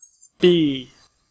File:En-us-b.ogg